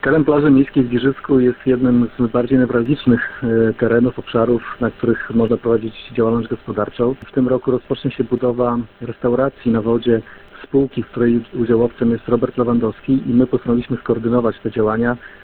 Jak mówi Wojciech Karol Iwaszkiewicz, burmistrz Giżycka te działania mogłyby współgrać z inną inwestycją.